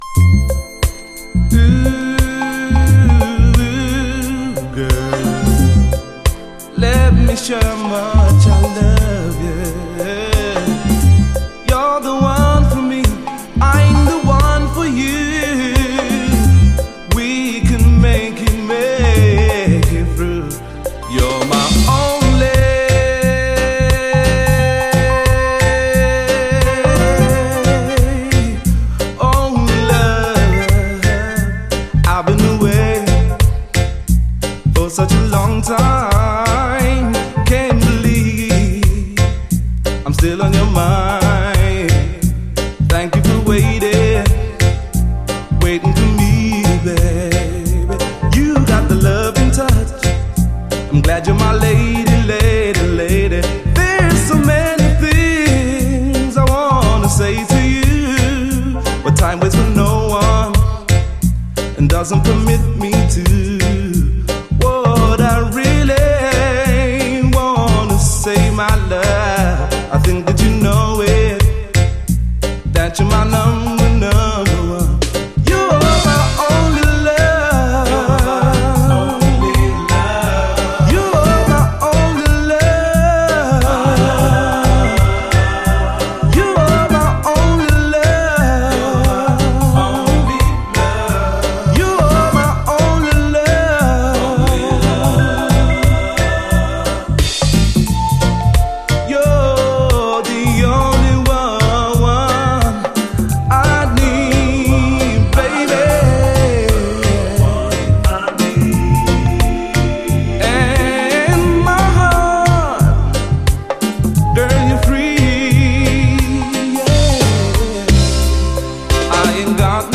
REGGAE
90’SメロウR&B的なしっとり系90’Sラヴァーズ！